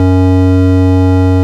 Index of /90_sSampleCDs/Keyboards of The 60's and 70's - CD1/ORG_FarfisaCombo/ORG_FarfisaCombo
ORG_VIP Bch  F#2.wav